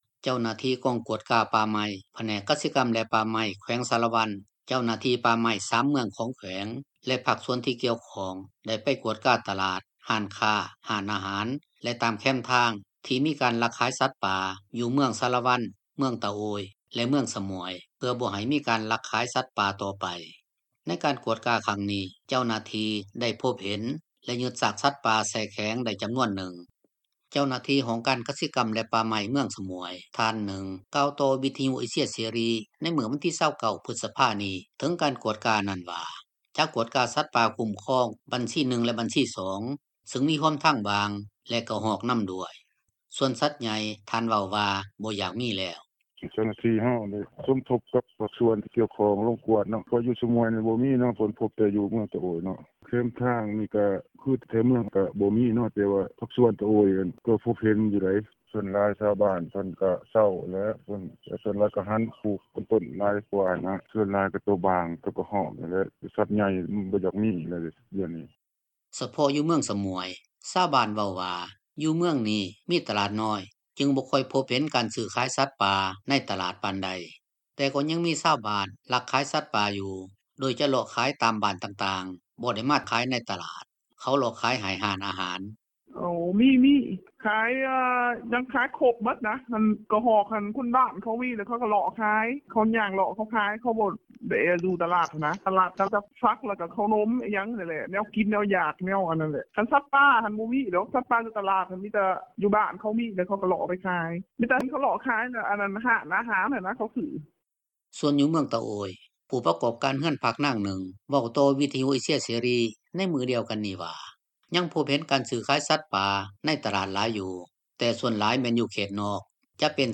ສ່ວນຢູ່ເມືອງ ຕະໂອ້ຍ, ຜູ້ປະກອບການເຮືອນພັກນາງນຶ່ງ ເວົ້າຕໍ່ວິທຍຸເອເຊັຽເສຣີ ໃນມື້ດຽວກັນນີ້ວ່າ ຍັງພົບເຫັນການຊື້-ຂາຍສັດປ່າໃນຕະຫລາດຫລາຍຢູ່, ແຕ່ສ່ວນຫລາຍ ແມ່ນຢູ່ເຂດນອກ, ຈະເປັນສັດນ້ອຍ ເປັນຕົ້ນ ກະຮອກ ແລະແລນ ທີ່ຊາວບ້ານລ້າມາໄດ້.